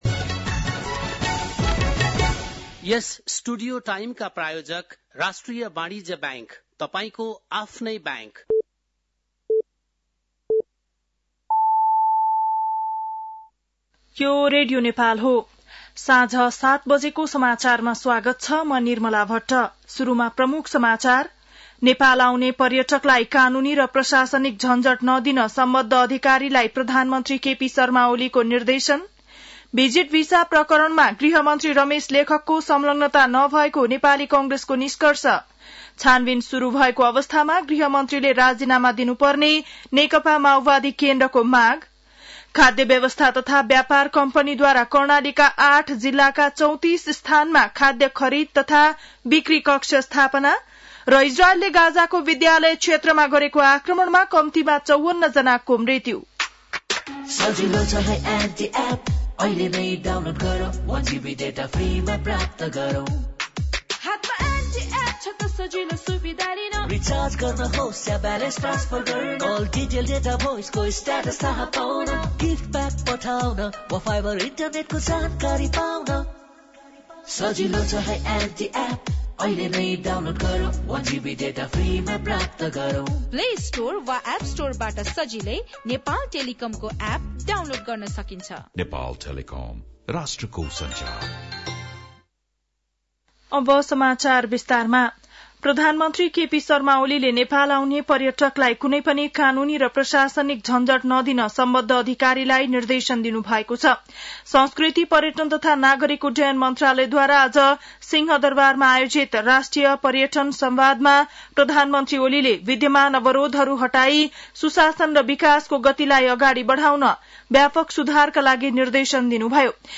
बेलुकी ७ बजेको नेपाली समाचार : १२ जेठ , २०८२
7.pm-nepali-news-1-2.mp3